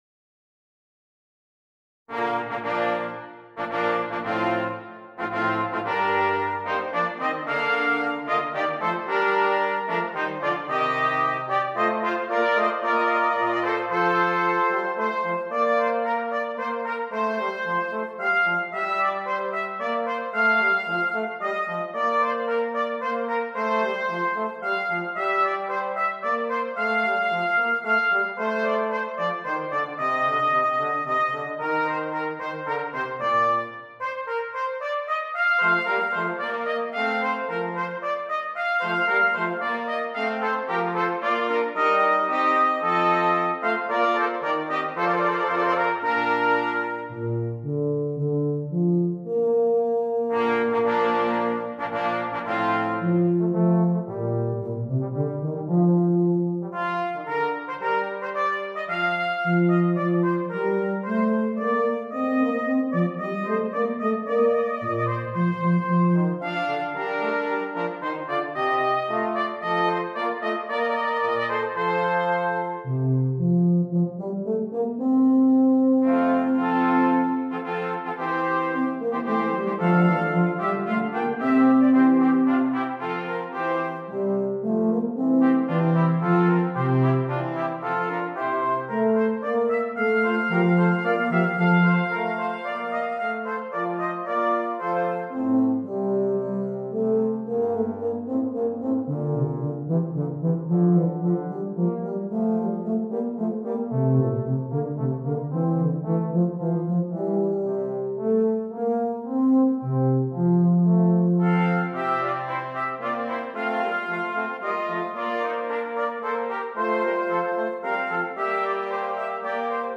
Brass Quintet
This is a duet for tuba and trumpet.